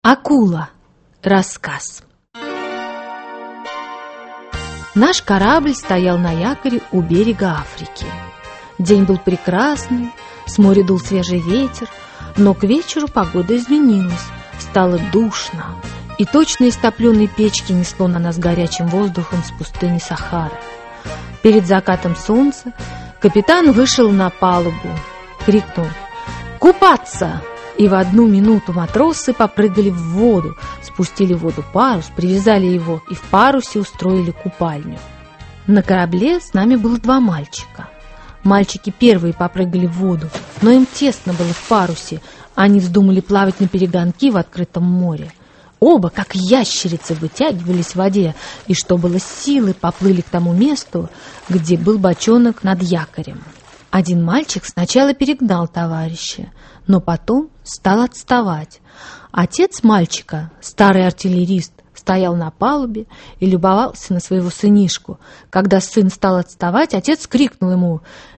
Aудиокнига Рассказы и сказки Автор Лев Толстой Читает аудиокнигу Александр Леньков.